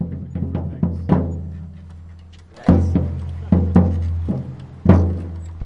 青蛙圈0001
描述：循环的青蛙的现场记录。
Tag: 自然 现场录音 青蛙 环境 循环 实验性